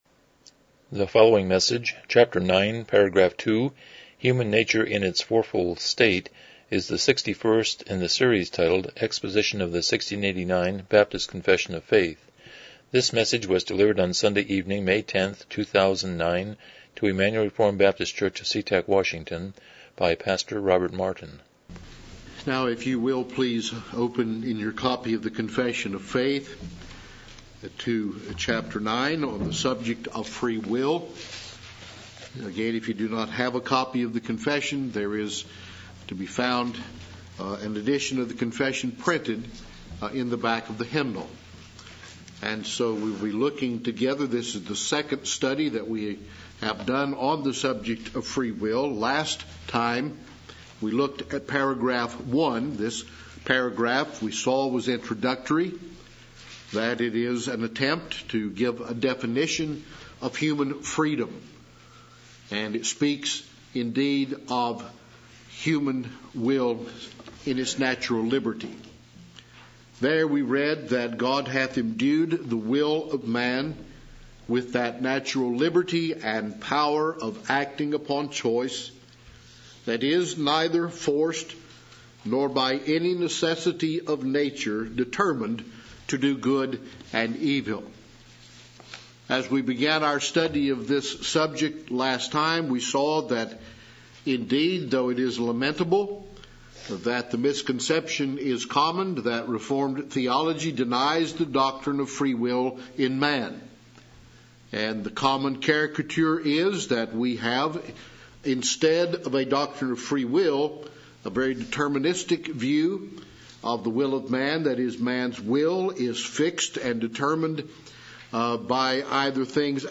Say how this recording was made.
1689 Confession of Faith Service Type: Evening Worship « 79 Romans 6:19-22 32 The Abrahamic Covenant